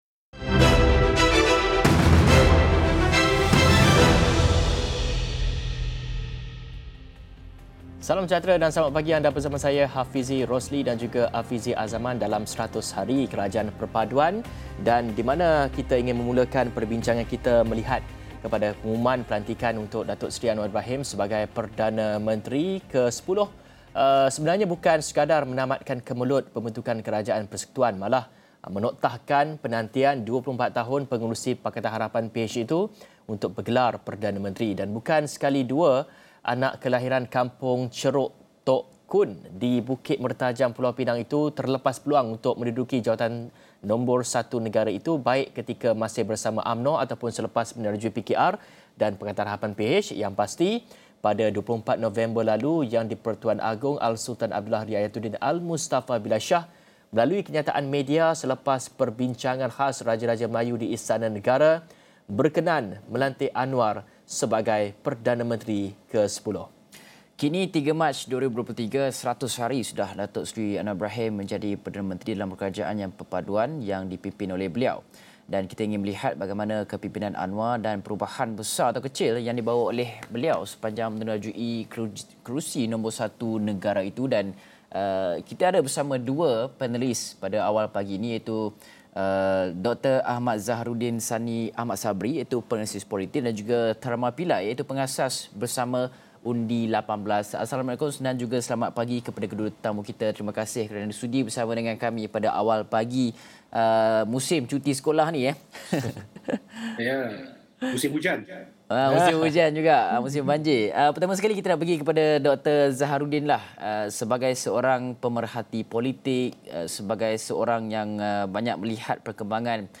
ikuti diskusi bersama Penganalisis Politik